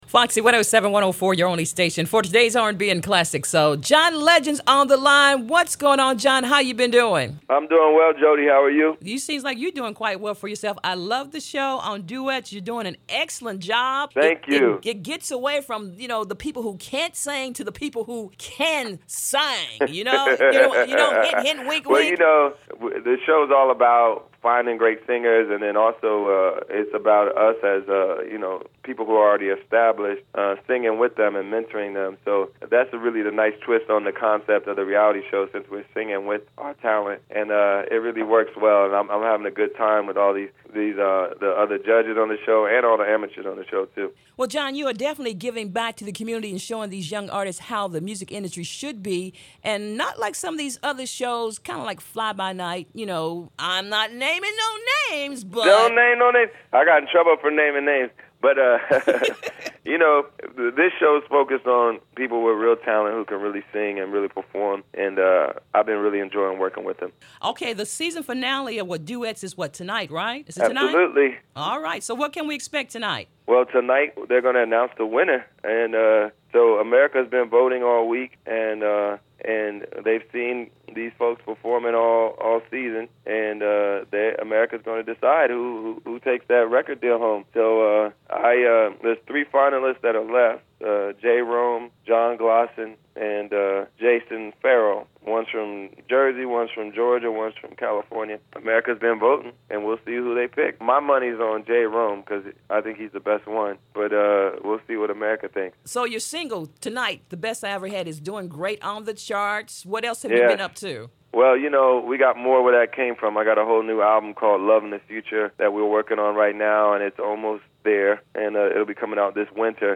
Interview: John Legend
john-legend-interview.mp3